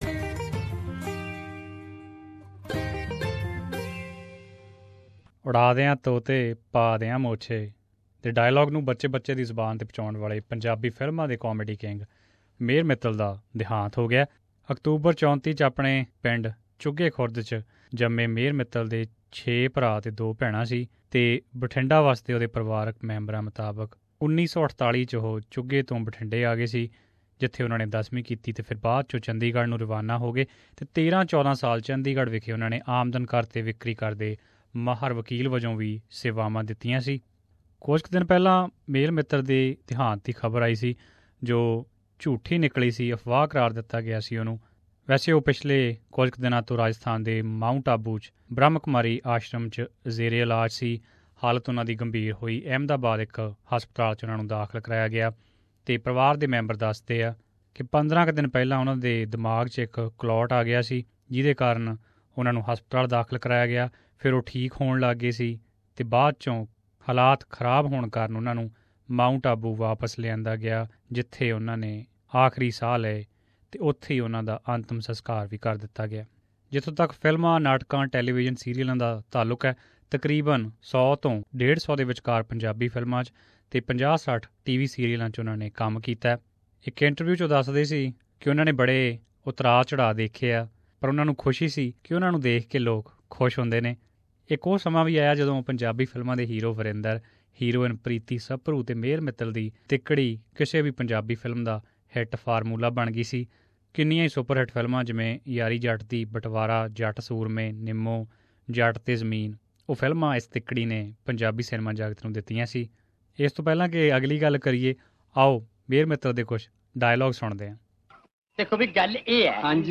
In this segment, we collated some of Mehar Mittals famous dialogues and skits from Punjabi movies. You can also listen to how Punjabi film industry celebrities reacted on his sad demise.